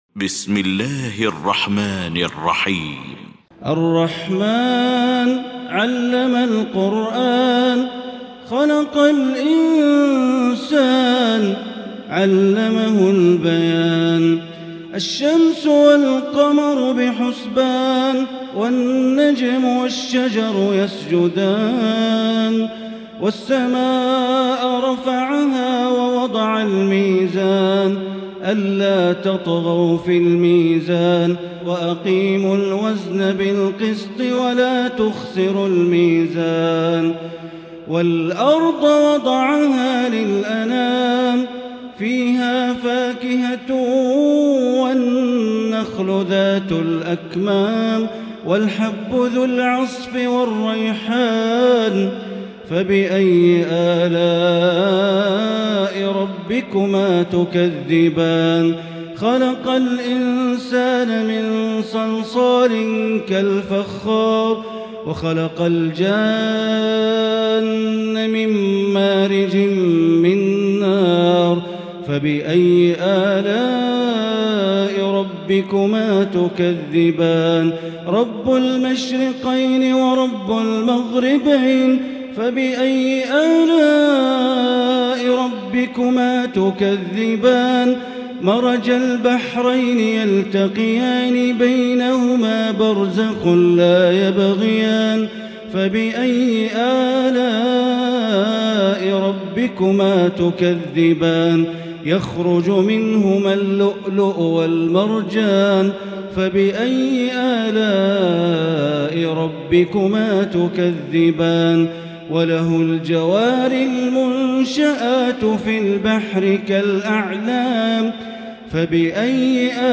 المكان: المسجد الحرام الشيخ: معالي الشيخ أ.د. بندر بليلة معالي الشيخ أ.د. بندر بليلة الرحمن